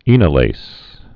(ēnə-lās, -lāz)